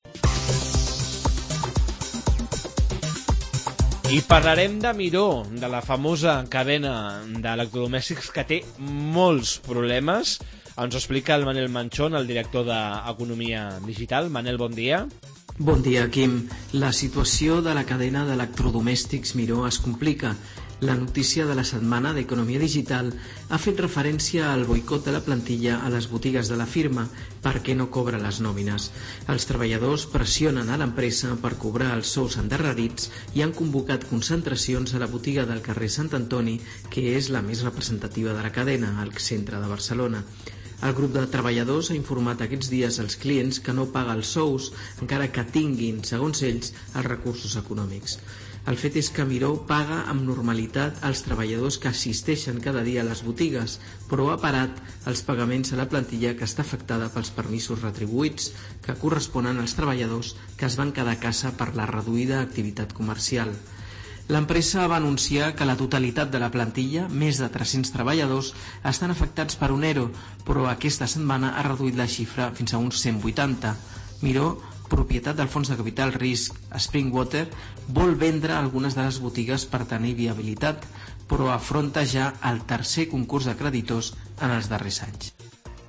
La crisi de la cadena de botigues Miró. Entrevista